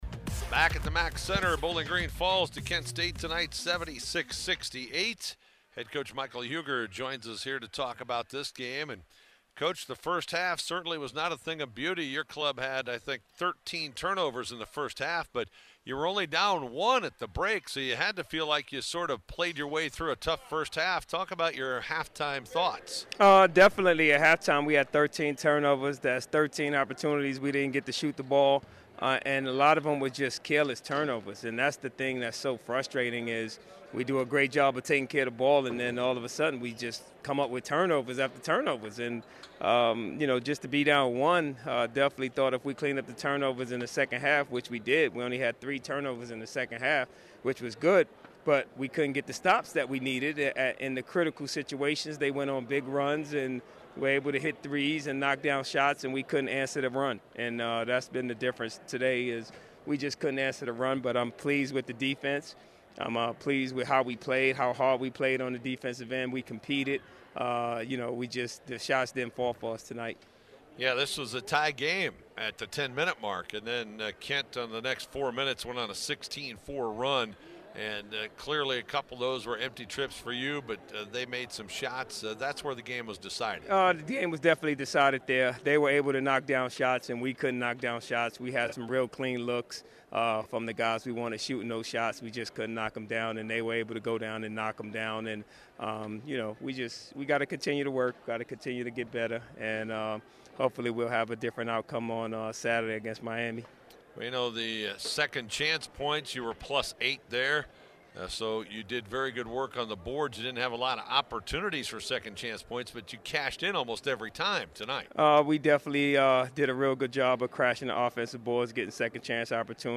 Full postgame interview